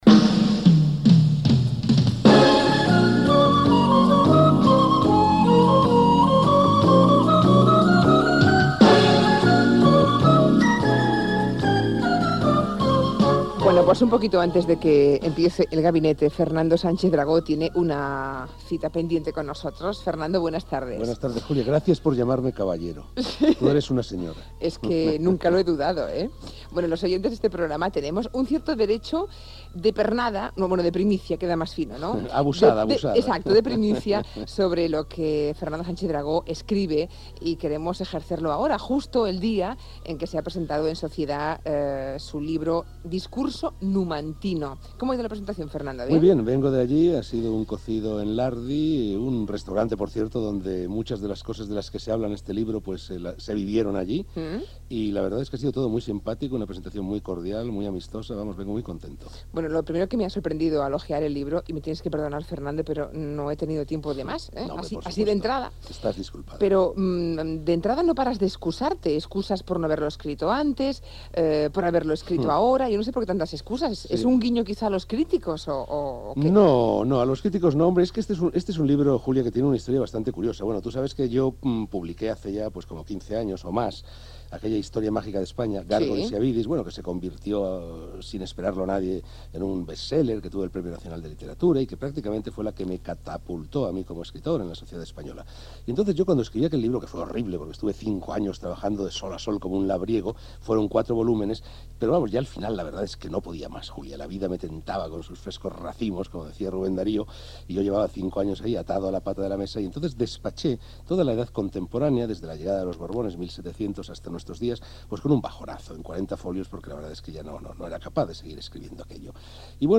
Entrevista a Fernando Sánchez Dragó que publicava el llibre "Discurso numantino"
Info-entreteniment